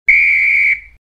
Звуки свистка
Звук свистка один вариант